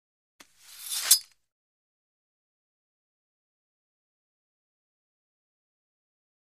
Sword: Remove From Sheath; Metal Blade Quickly Removed From Sheath. Close Perspective.